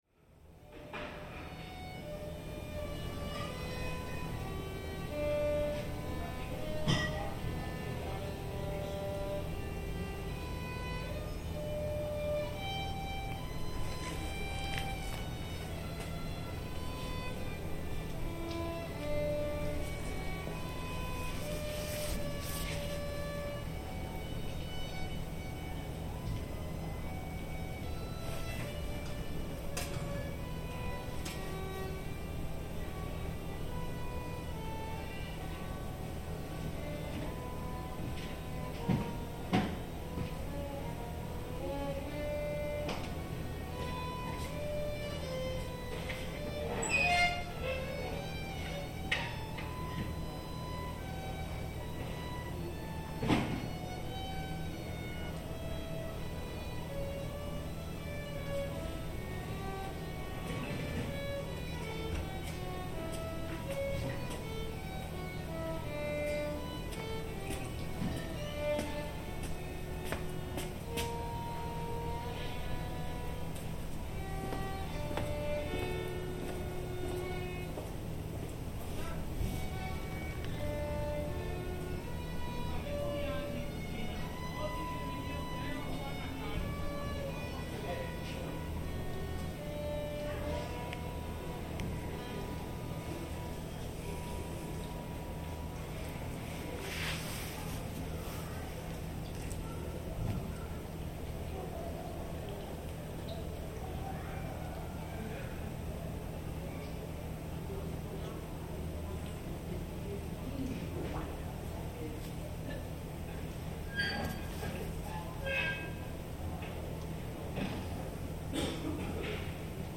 Violin practice in Venice
Violin practice drifts down from a window over an otherwise silent canal in Venice, Italy - a moment of rare peace off the beaten track in the centre of the city.